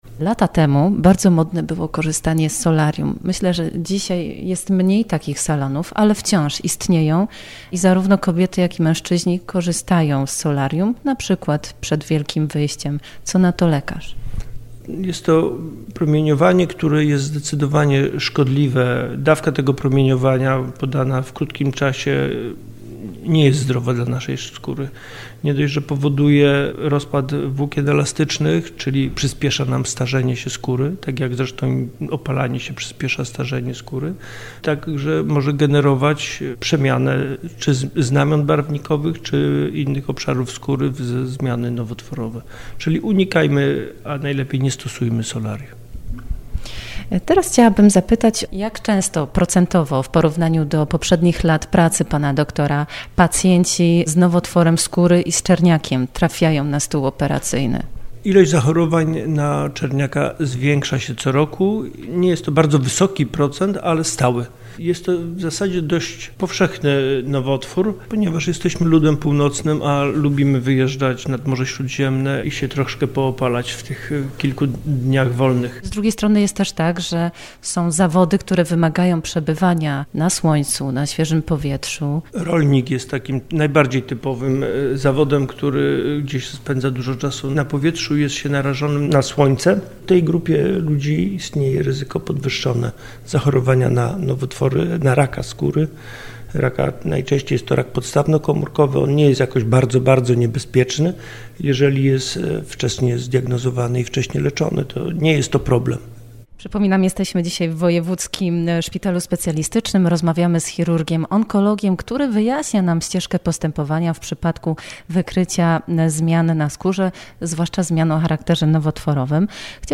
Emisja audycji już dziś (czwartek, 21 grudnia) po godz. 11:00.